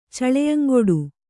♪ caḷeyaŋgoḍu